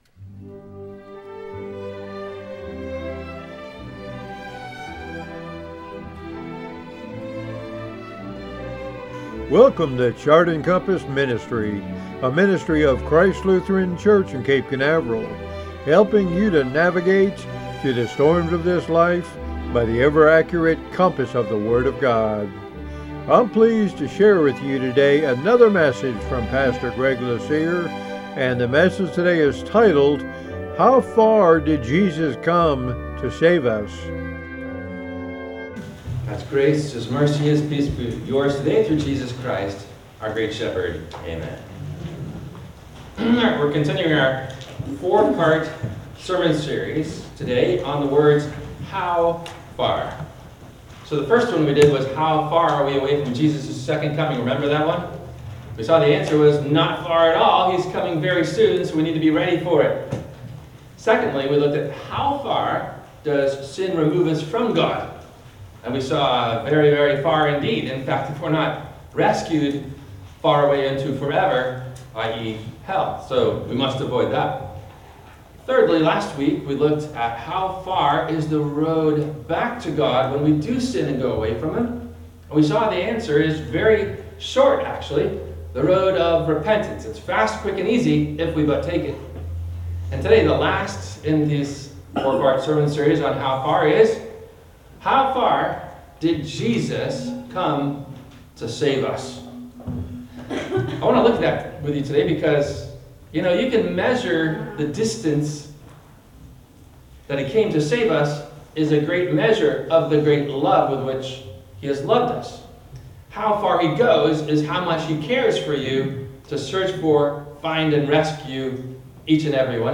No Questions asked before the Sermon message:
WMIE Radio – Christ Lutheran Church, Cape Canaveral on Mondays from 12:30 – 1:00